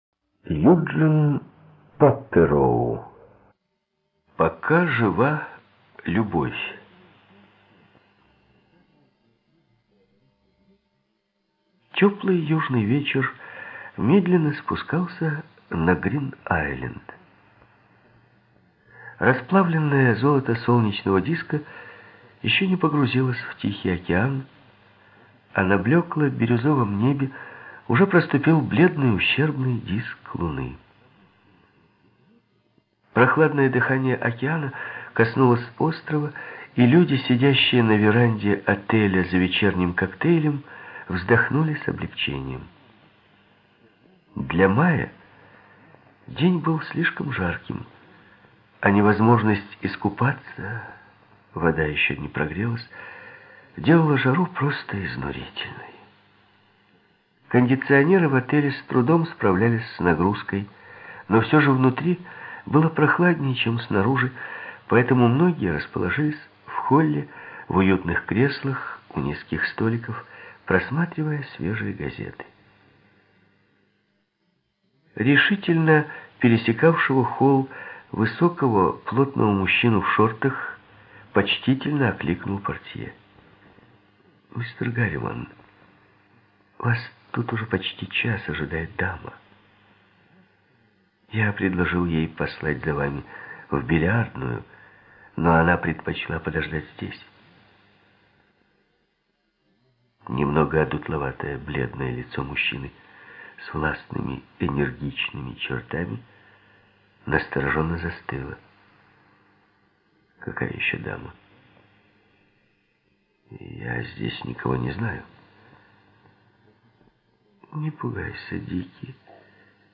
Студия звукозаписиРеспубликанский дом звукозаписи и печати УТОС